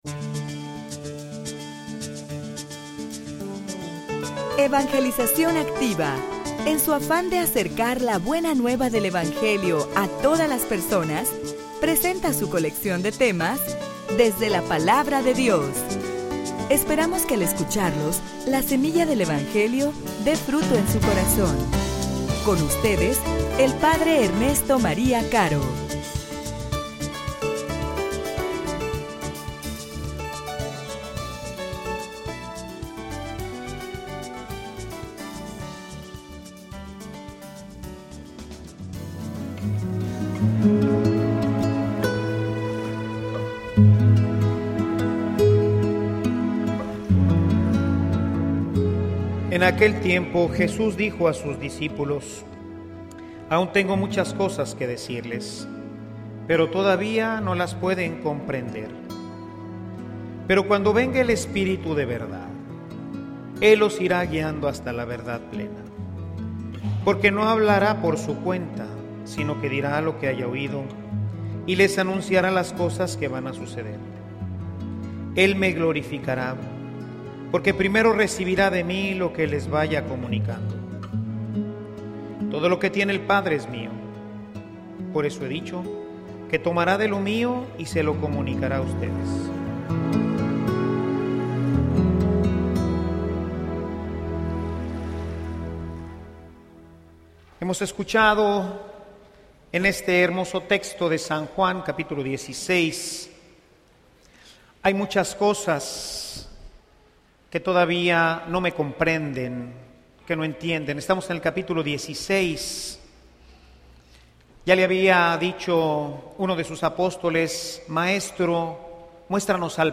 homilia_Si_te_conocieran_oh_amado_Padre.mp3